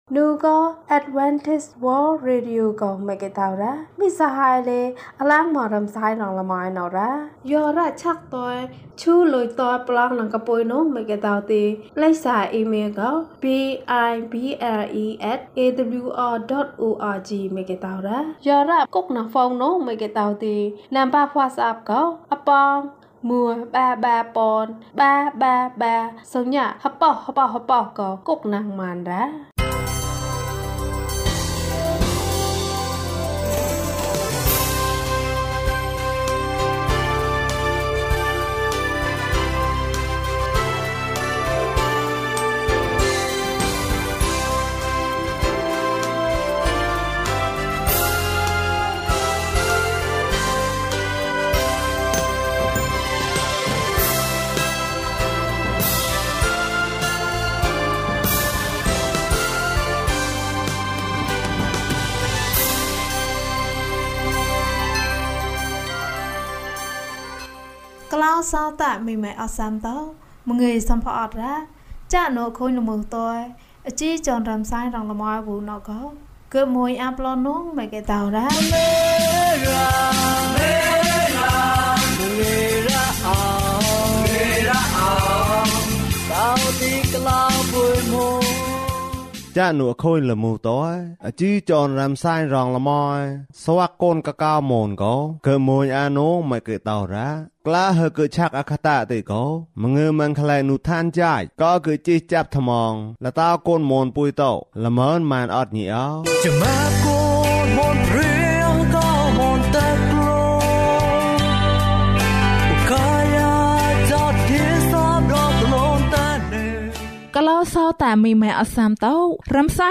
ခရစ်တော်ထံသို့ ခြေလှမ်း။၄၄ ကျန်းမာခြင်းအကြောင်းအရာ။ ဓမ္မသီချင်း။ တရားဒေသနာ။